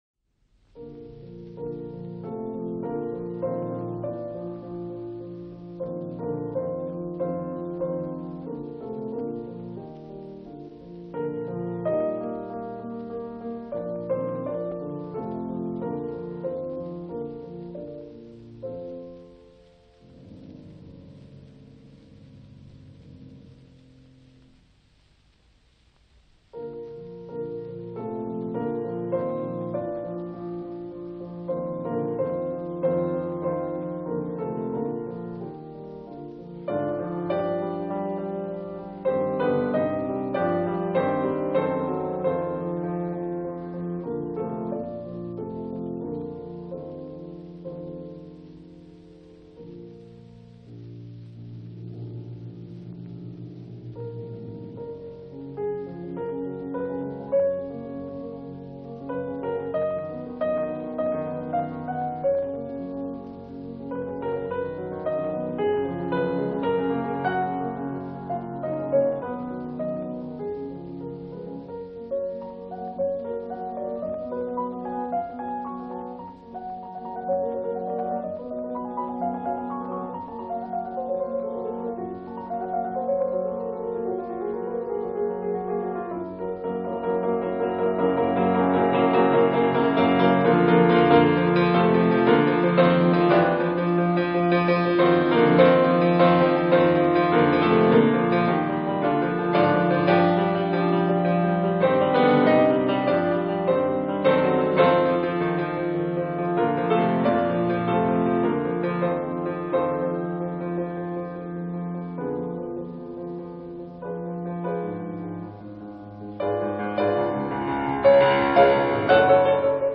MÚSICA CLÁSICA - El próximo 15 de agosto se cumplen 70 años del fallecimiento del pianista y compositor judío Artur Schnabel (nacido en Austria en 1882 y fallecido ya nacionalizado estadounidense en 1951).
escuchamos en una grabación de enero de 1939
Son los movimientos de la pieza presentada y que inicia el miniciclo que le dedicaremos: Molto moderato, Andante sostenuto, Scherzo. Allegro vivace con de